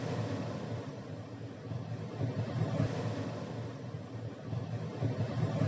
TurbineRoar.wav